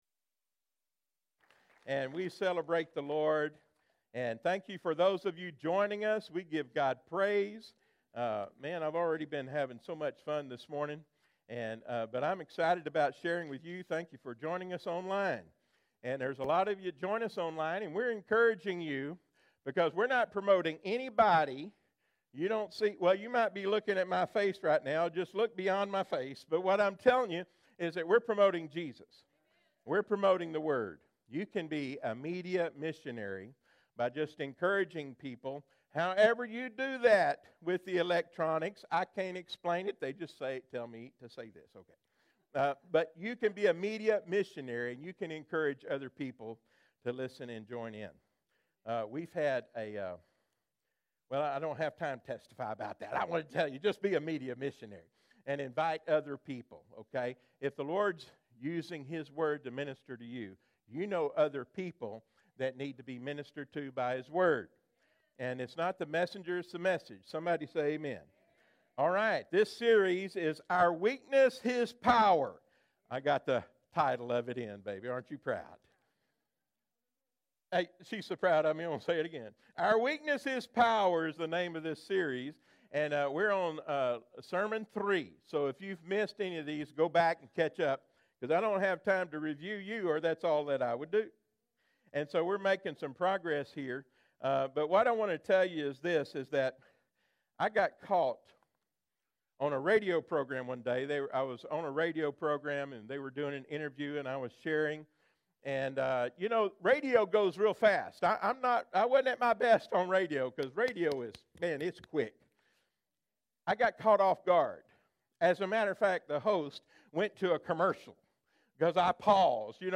Freedom Life Fellowship Live Stream